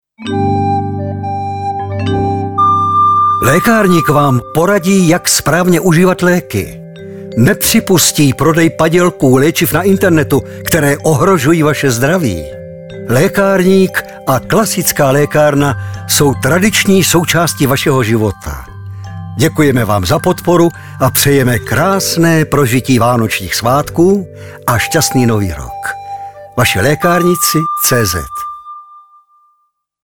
Pokračuje úspěšná kampaň na rádiu Frekvence 1 a rádiu Blaník. Hlasem Vašich lékárníků je i nadále charismatický herec Ladislav Frej.